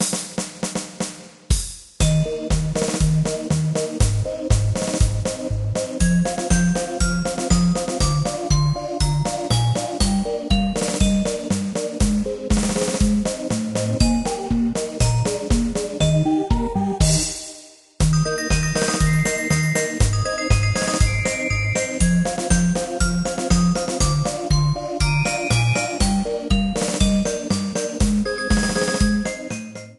Level results theme
trimmed & added fadeout You cannot overwrite this file.